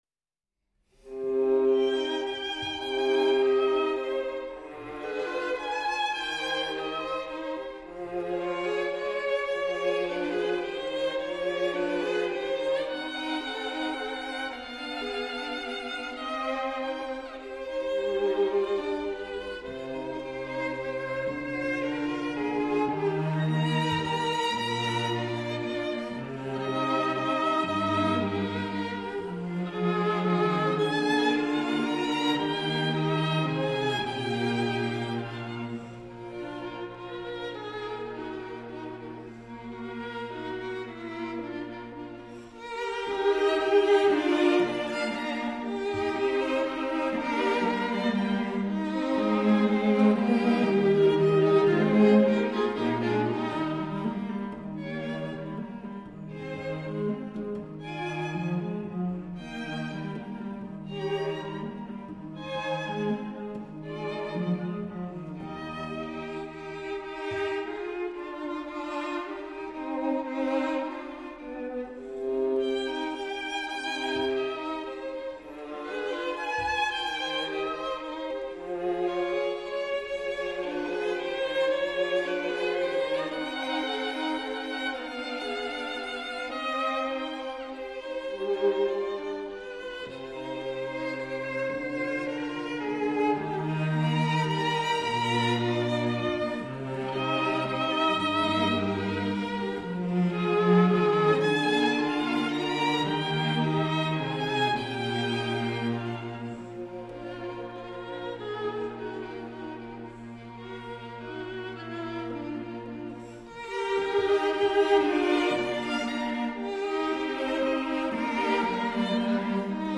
String Quartet in C major
Andante